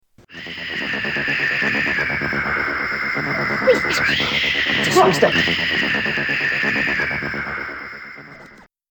Hissing like snakes